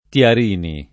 [ t L ar & ni ]